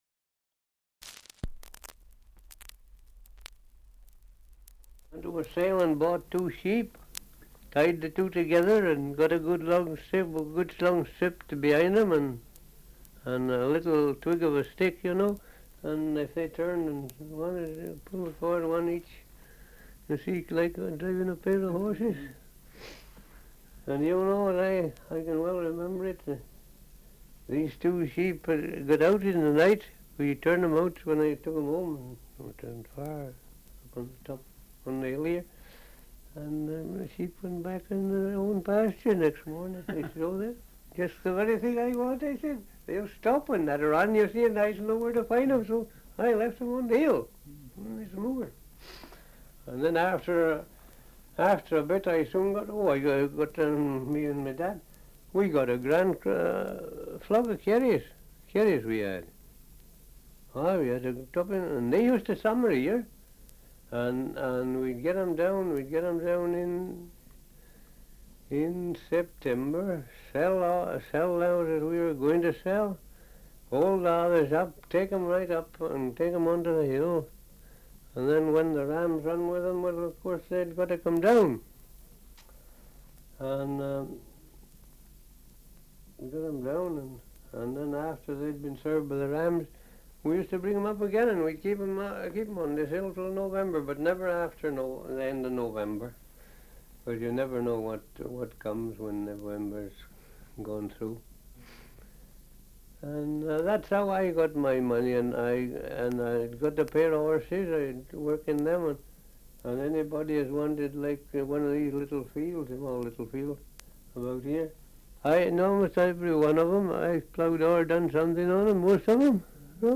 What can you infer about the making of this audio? Survey of English Dialects recording in All Stretton, Shropshire 78 r.p.m., cellulose nitrate on aluminium